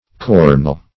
Search Result for " corneule" : The Collaborative International Dictionary of English v.0.48: Corneule \Cor"neule\ (k[^o]r"n[=u]l), n. [F., dim. of corn['e]e the cornea.]